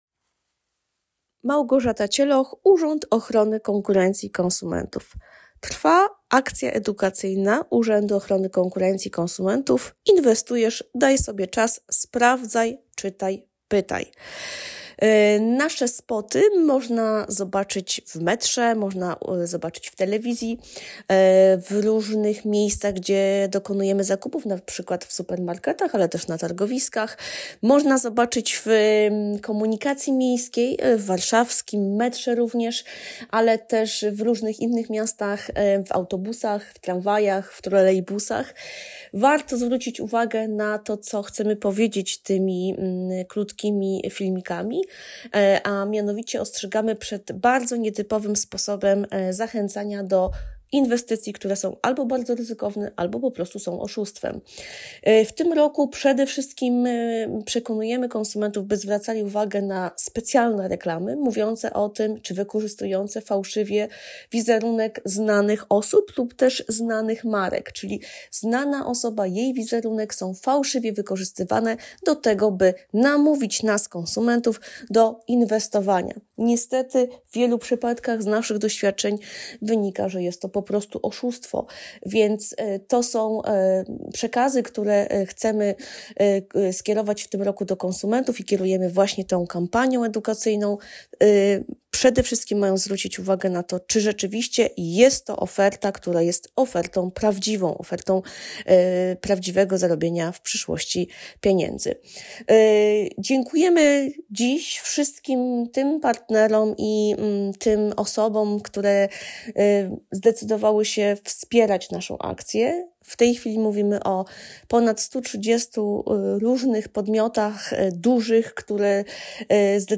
Wypowiedź Prezesa UOKiK Tomasza Chróstnego z 26 kwietnia 2024 r..mp3